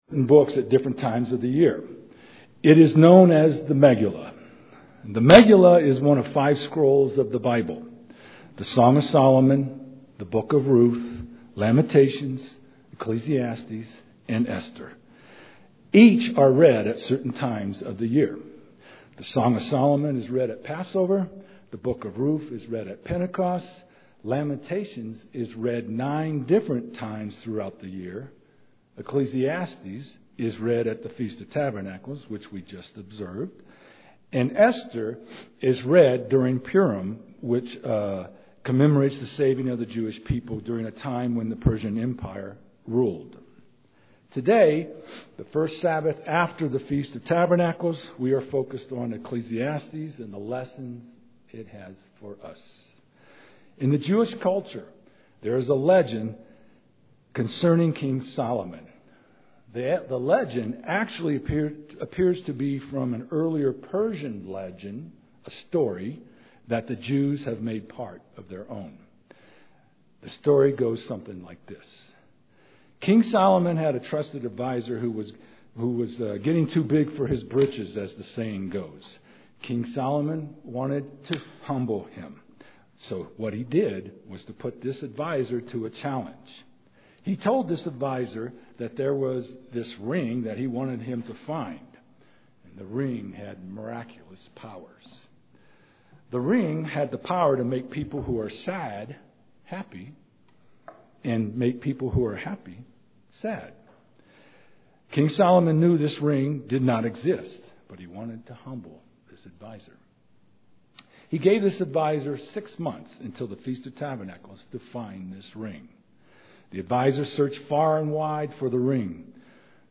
It is the spiritual dimension in life that is important. This sermon examines God's purpose to take us from the temporary to the eternal.